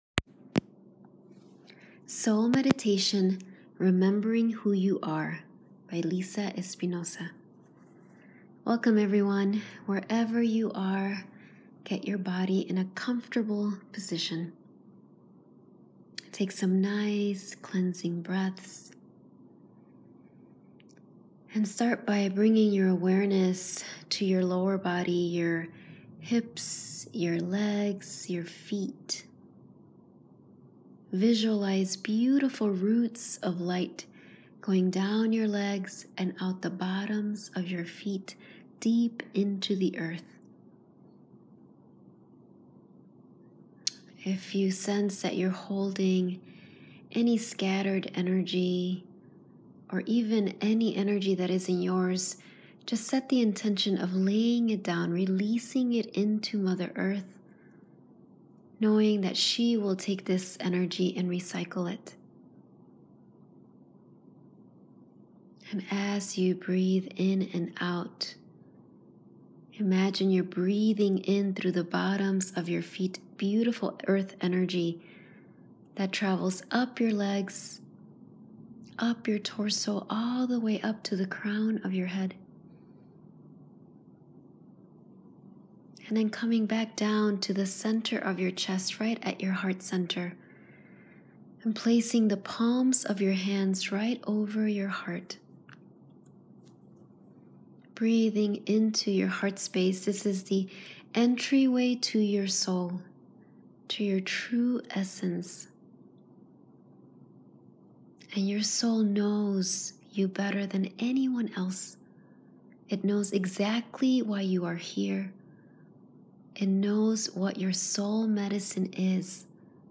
meditation-remember-who-you-are.m4a